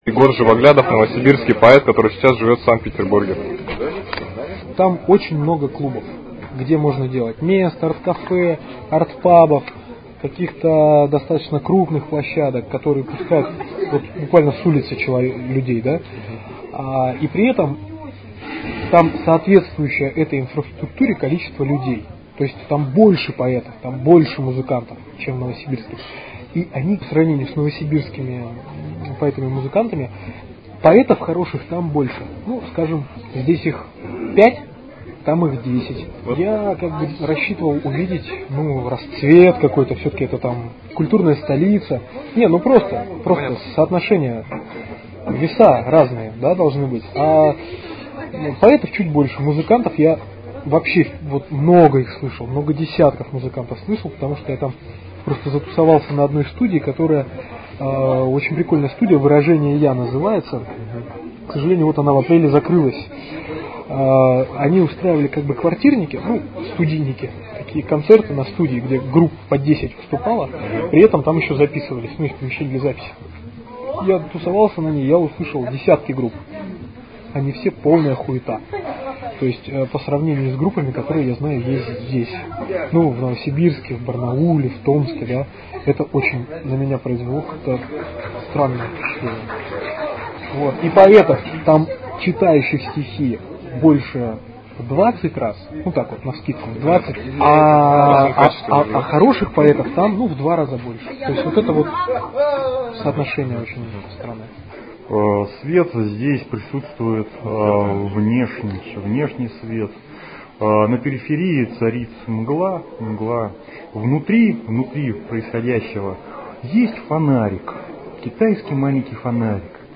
США в Интеграле - За кулисами Нарезки из разговоров происходивших за зрительским кадром внимания.